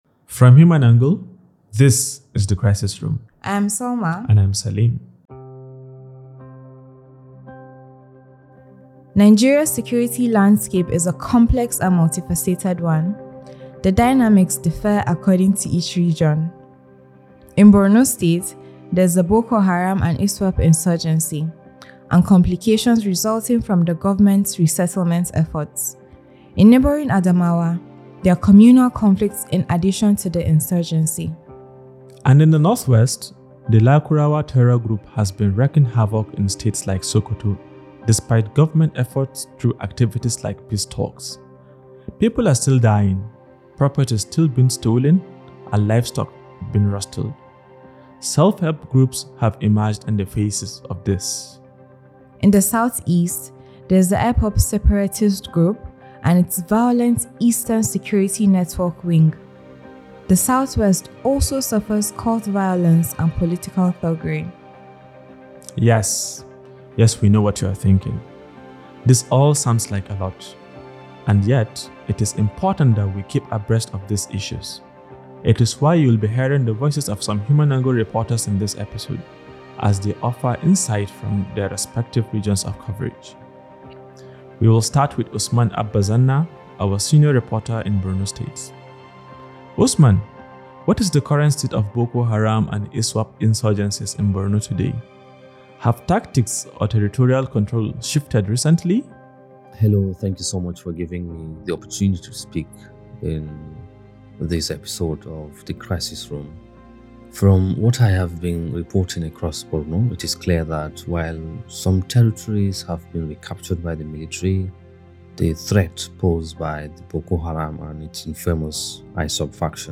In this episode, we will be hearing the voices of some HumAngle reporters as they offer insight from their respective regions of coverage.